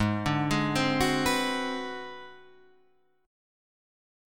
G#7#9b5 chord